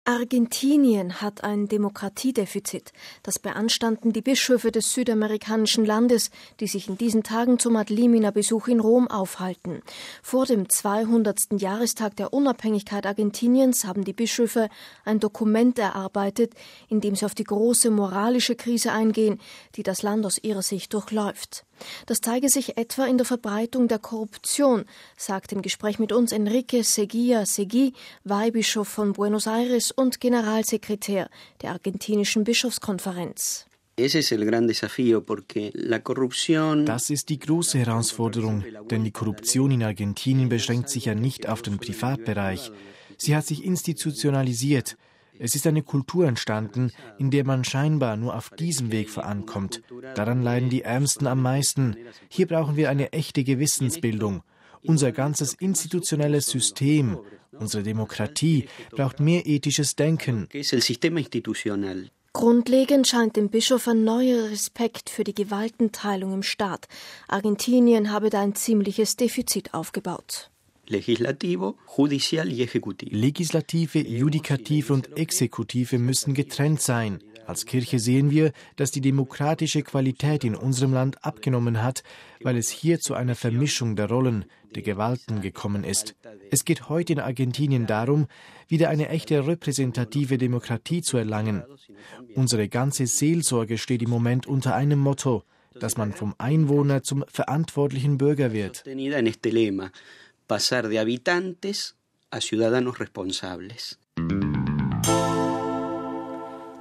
Vor dem 200. Jahrestag der Unabhängigkeit Argentiniens haben die Bischöfe ein Dokument erarbeitet, in dem sie auf die große moralische Krise eingehen, die das Land aus ihrer Sicht durchläuft. Das zeigt sich etwa in der Verbreitung der Korruption, sagte im Gespräch mit uns Enrique Eguía Seguí, Weihbischof von Buenos Aires und Generalsekretär der argentinischen Bischofskonferenz.